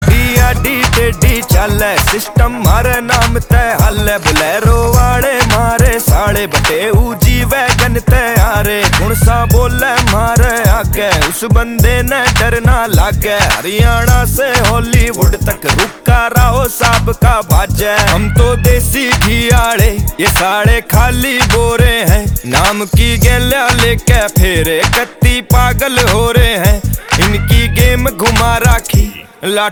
• Viral social media mashup vibe
• Catchy and energetic background music